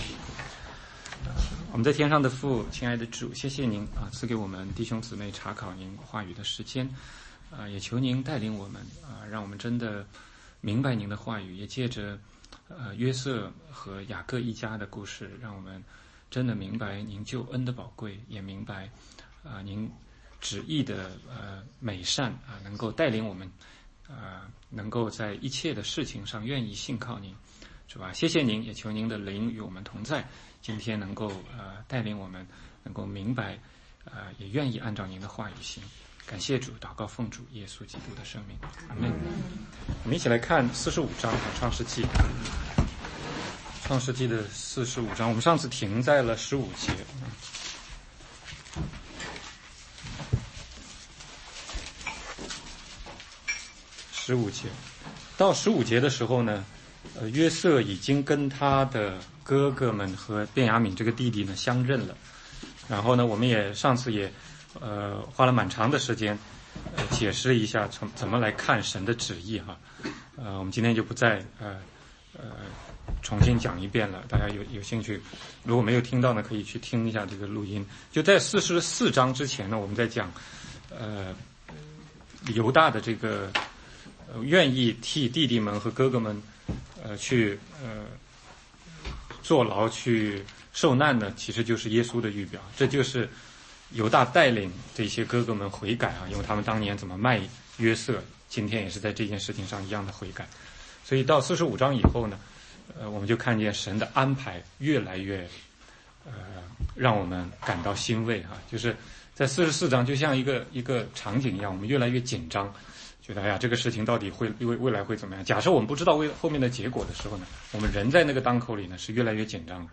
16街讲道录音 - 创世纪45:15 - 46:6